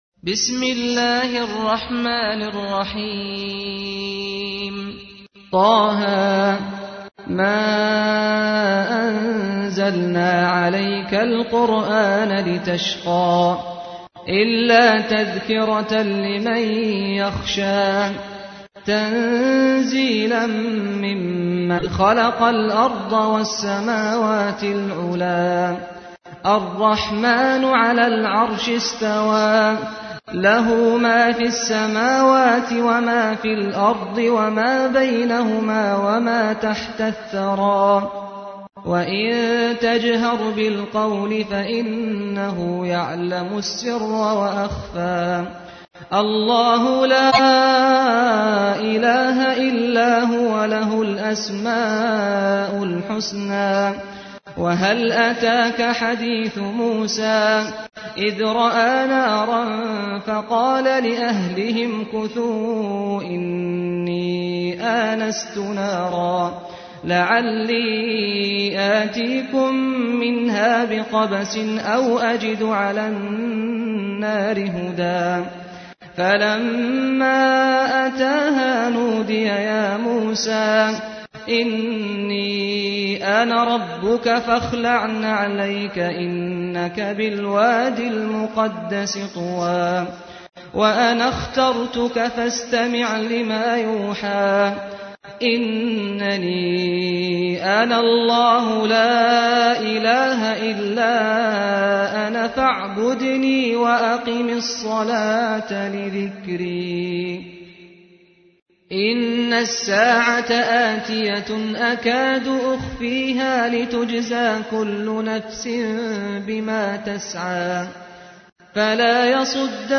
تحميل : 20. سورة طه / القارئ سعد الغامدي / القرآن الكريم / موقع يا حسين